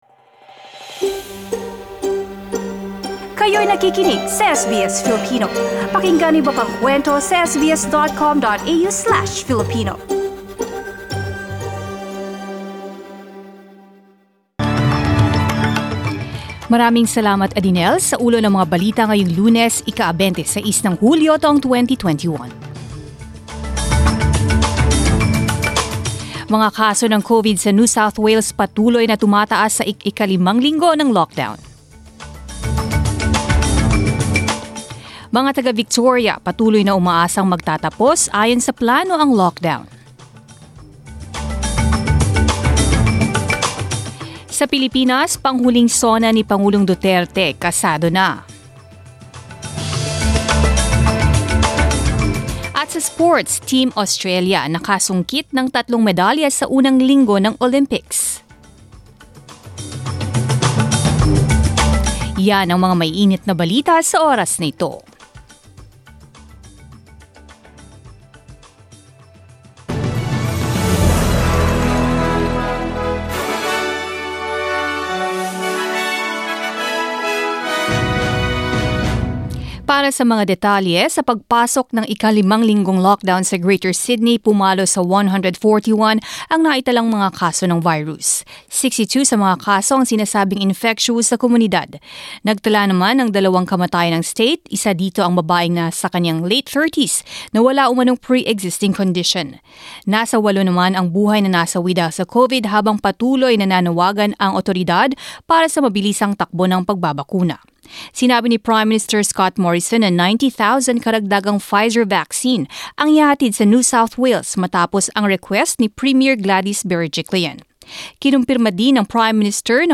Mga balita ngayong ika-26 ng Hulyo